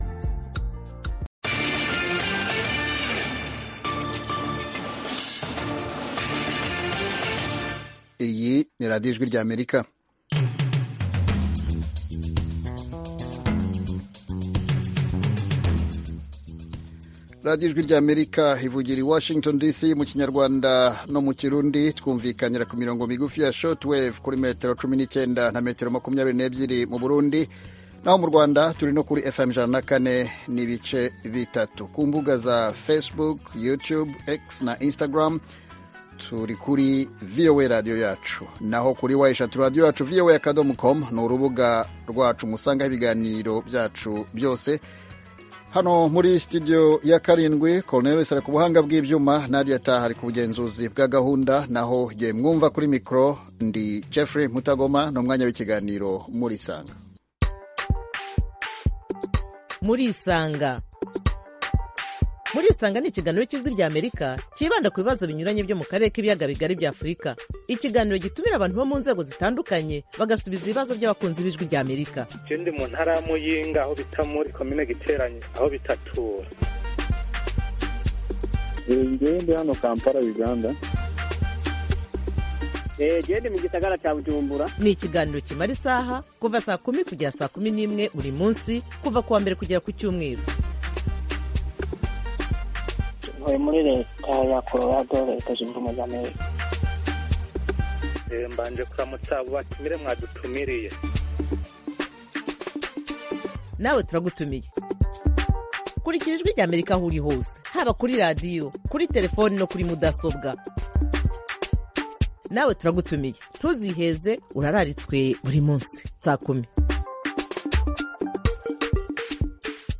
Mu kiganiro Murisanga cy’uyu munsi turakira muri studio z’Ijwi ry’Amerika umuhanzi uzwi mu ndirimbo zo kuramya no guhimbaza Imana akaba n’umuvugabutumwa